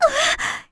Erze-Vox_Damage_kr_01.wav